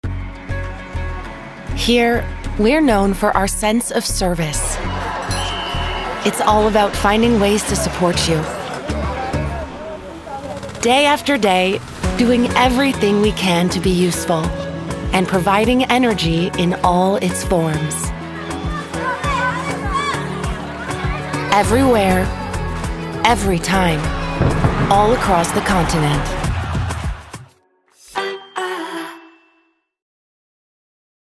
Female
Approachable, Assured, Confident, Conversational, Corporate, Energetic, Engaging, Natural
Microphone: Rode Nt1-A